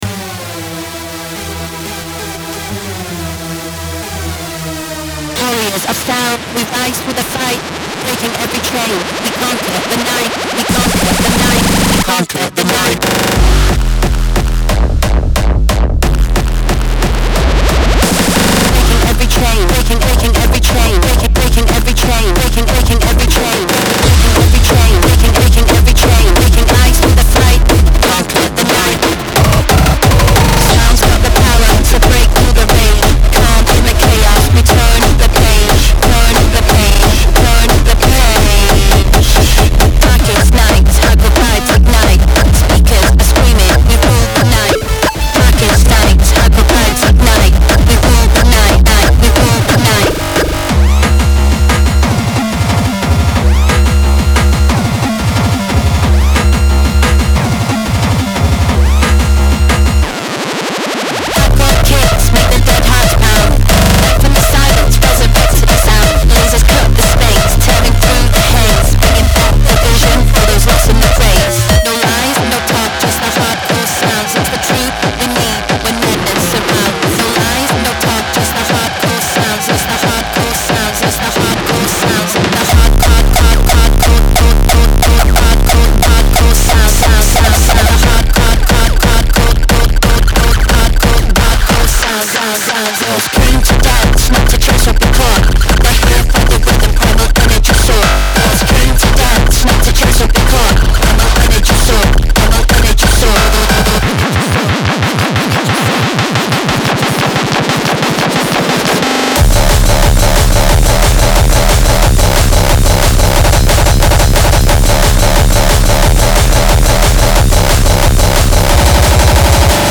Genre:Hardcore
これは純粋な180 BPMのカオスであり、無駄なものも情けもなく、ただただハードコアの嵐が吹き荒れます。
デモサウンドはコチラ↓
180 BPM
111 Hardcore Kick Loops
40 Synth Loops
43 Vocals (18 Dry, 25 Wet)